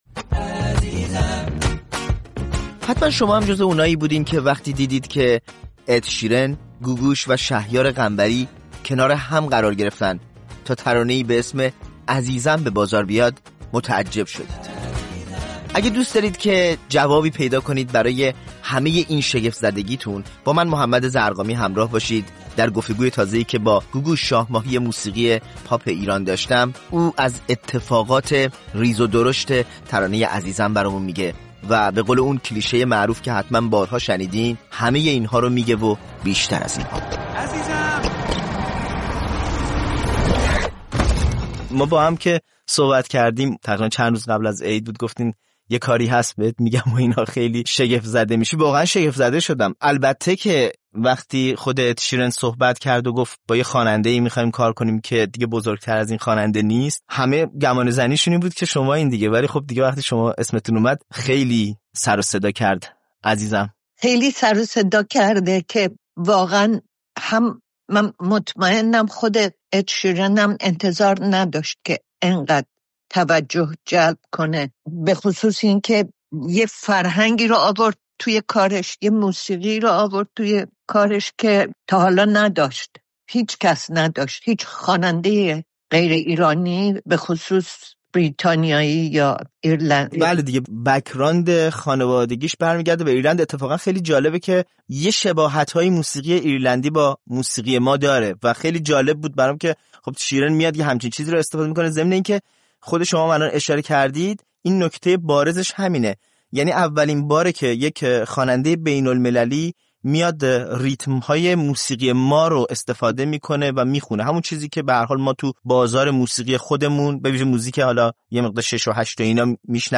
گوگوش شاه ماهی موسیقی پاپ ایران در گفت‌وگو با رادیو فردا از آغاز سال ۱۴۰۴ می‌گوید که با انتشار قطعه فراگیر «عزیزم» درکنار اِد شیرن، خواننده بریتانیایی، ورق خورد.